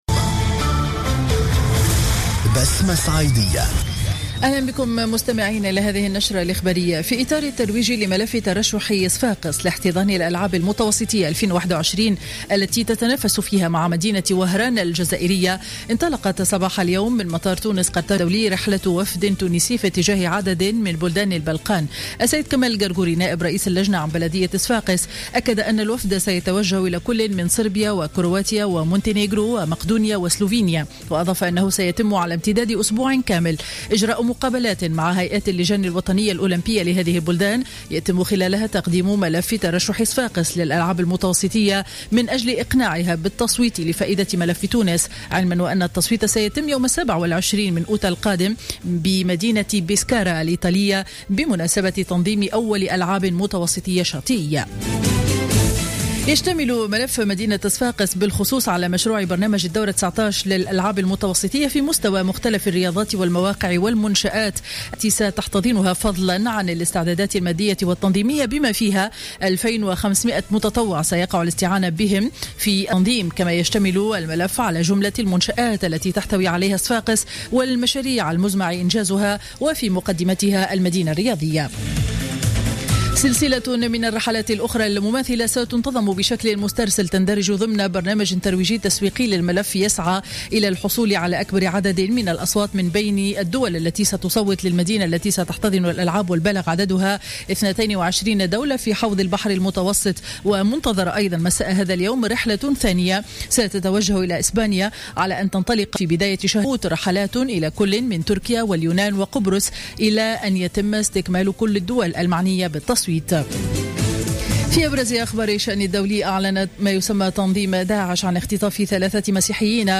نشرة أخبار منتصف النهار ليوم الأحد 19 جويلية 2015